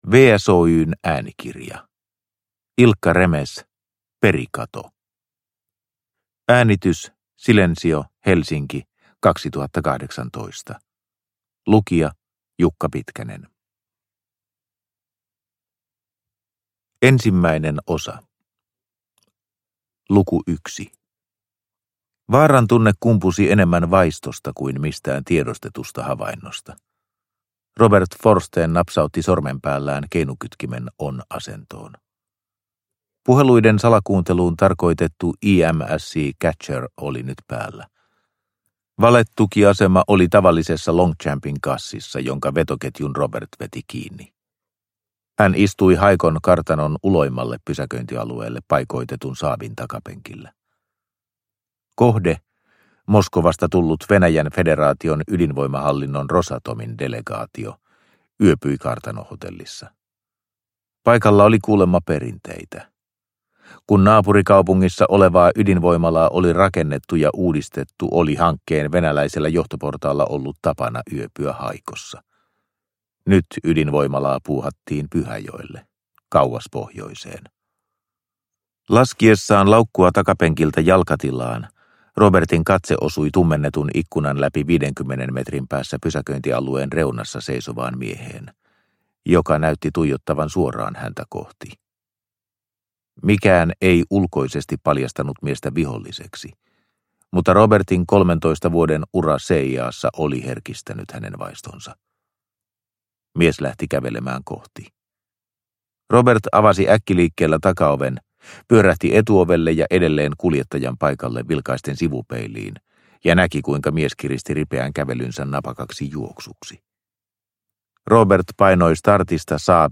Perikato – Ljudbok – Laddas ner